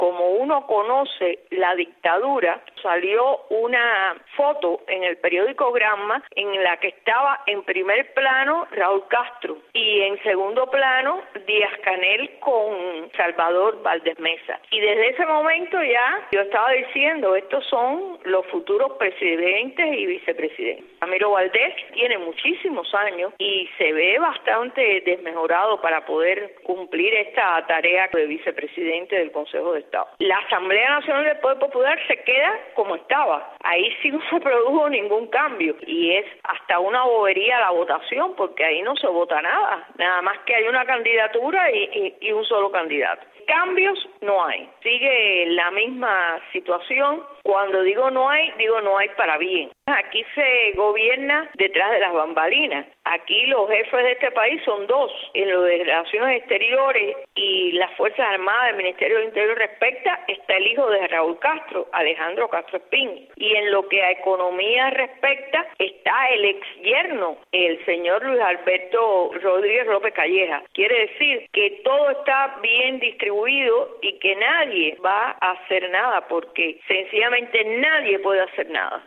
entrevista telefónica desde La Habana
Declaraciones